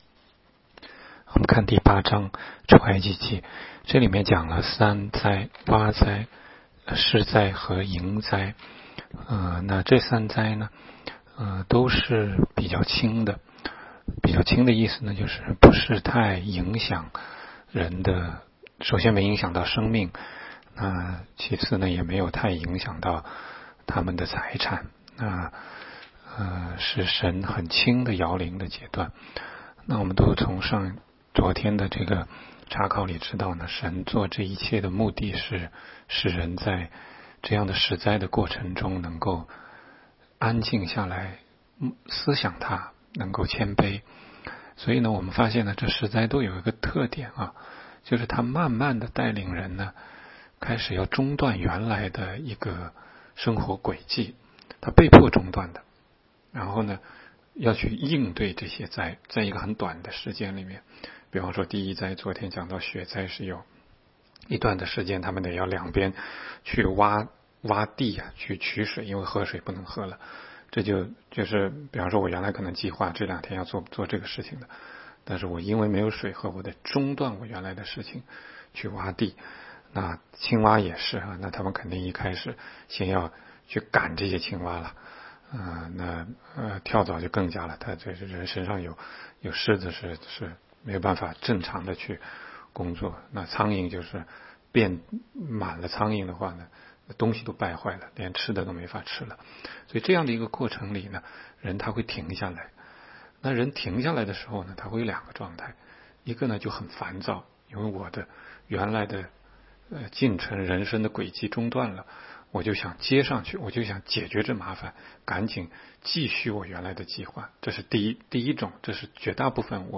16街讲道录音 - 每日读经-《出埃及记》8章